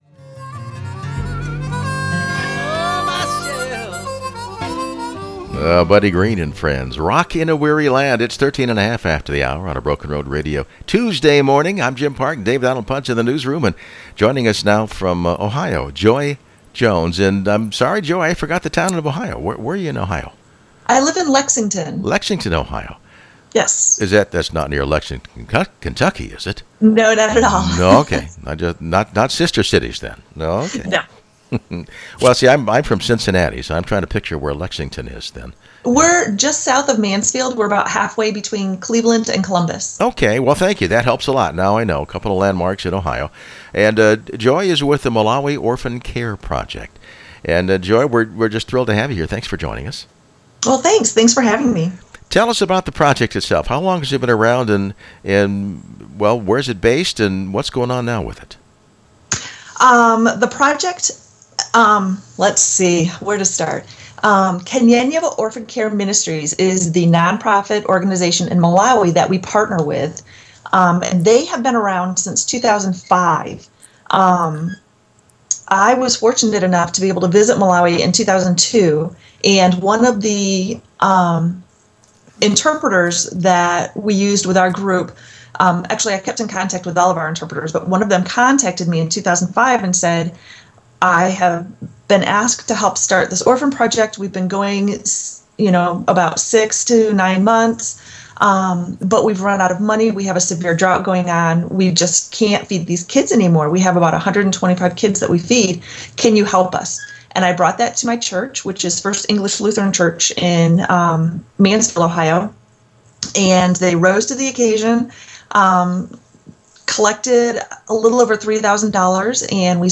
due to an inadvertent error, the second portion of our interview